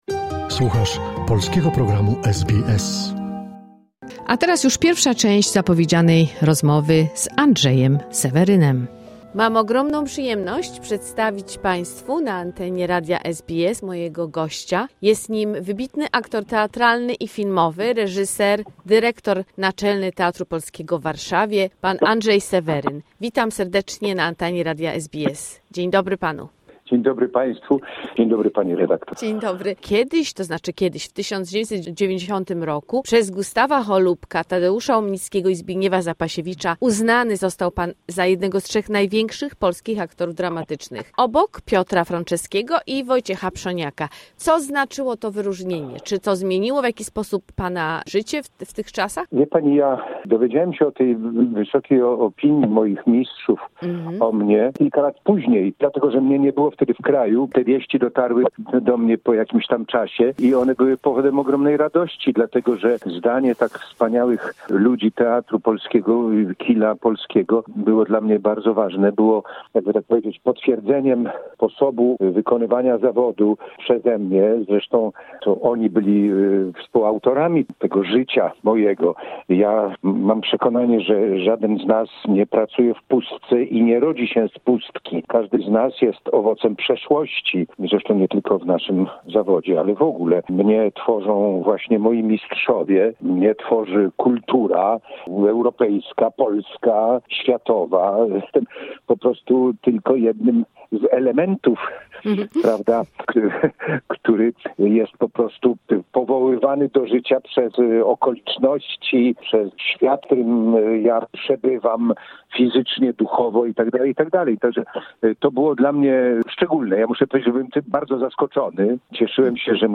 Andrzej Seweryn - a conversation with an outstanding theatre and film actor - part 1